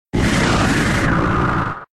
Cri d'Alakazam K.O. dans Pokémon X et Y.